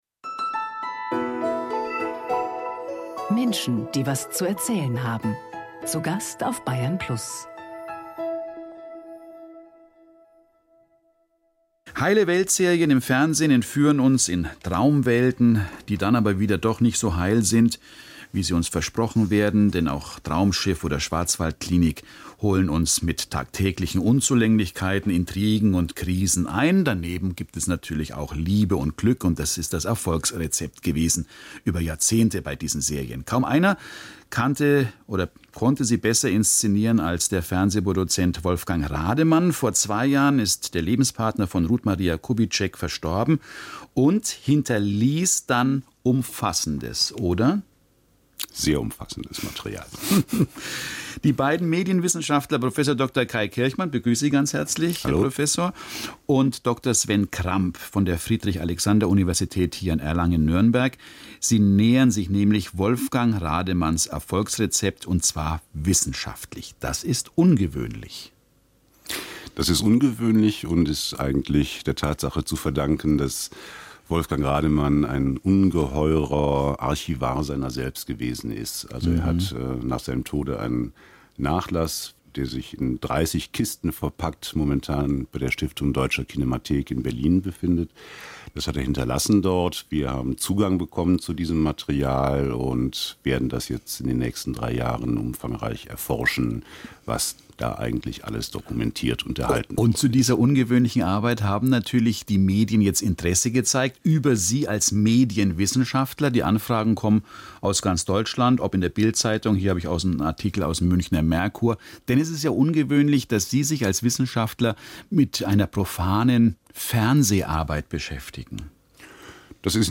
BR Radio Interview vom 06.11.2018